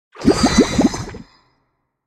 Sfx_creature_brinewing_death_01.ogg